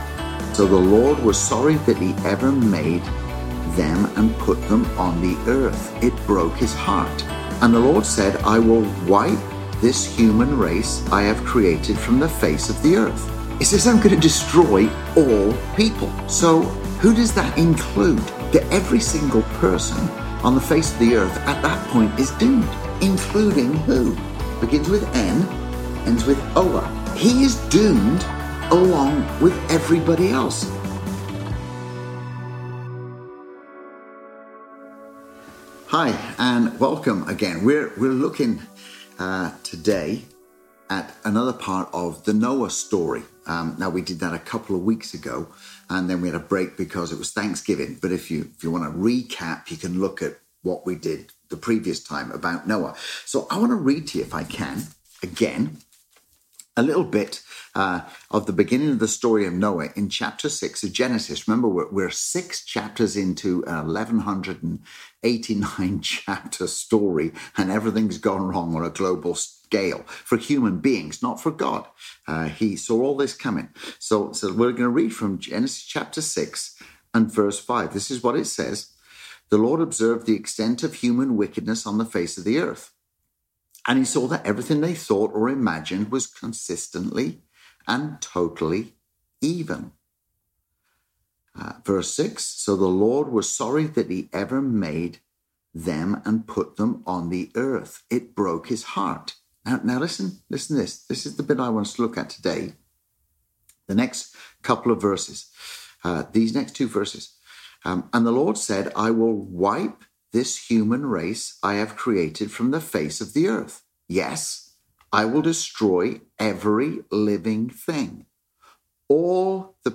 Sermons | Pemberton Community Church